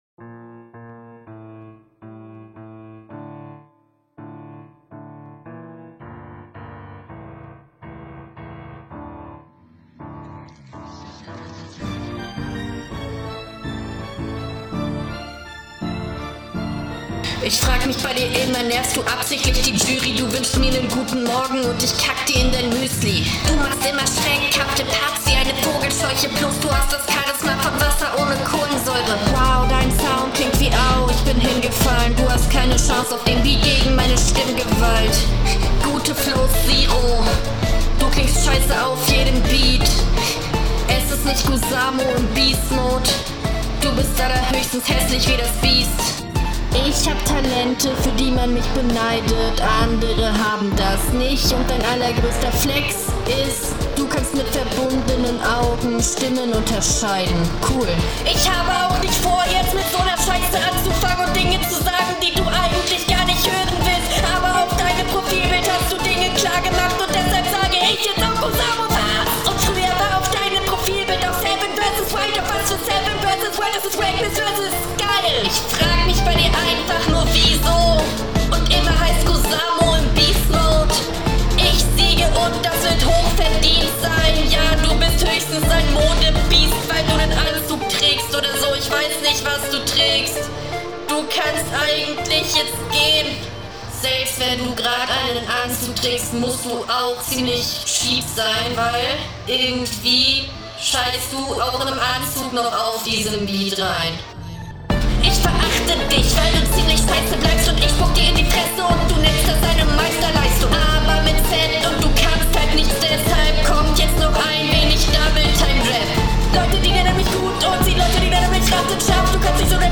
Eigentlich sehr gleich wie letzte Runde, aber flowlich ist das schon noch besser.